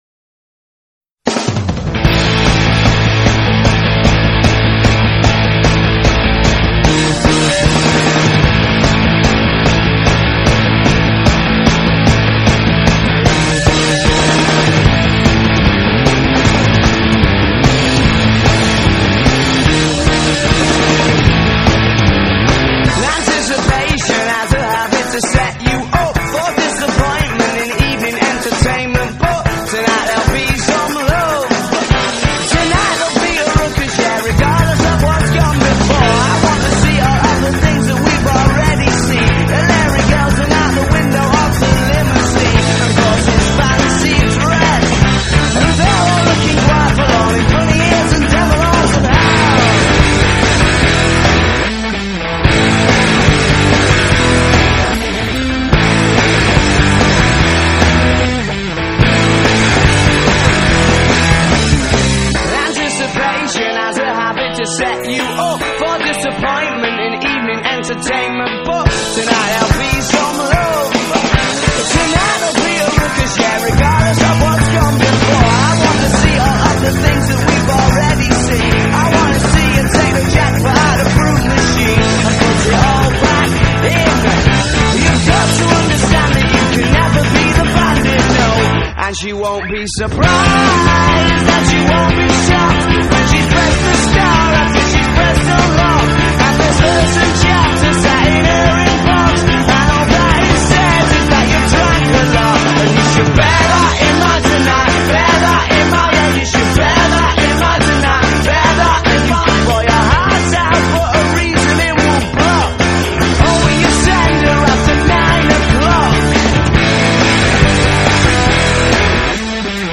Indie Rock, Garage Rock Revival, Post-Punk Revival